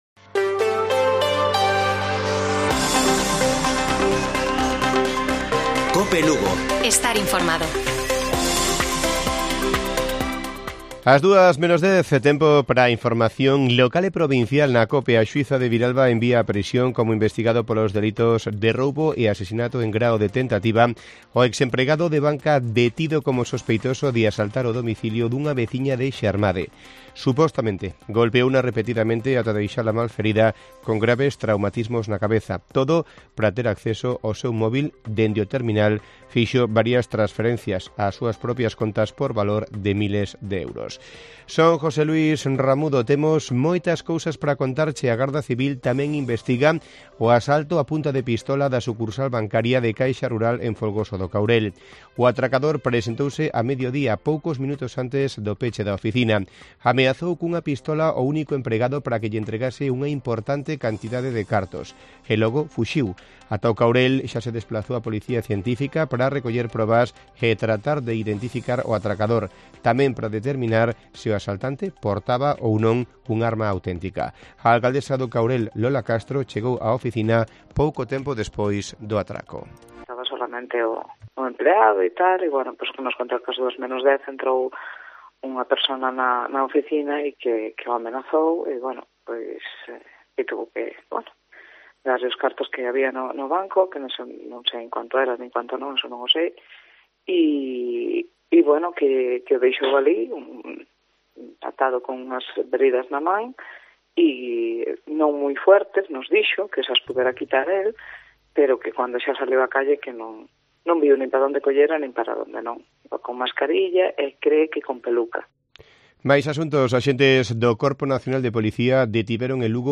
Informativo Mediodía de Cope Lugo. 28 de abril. 13:50 horas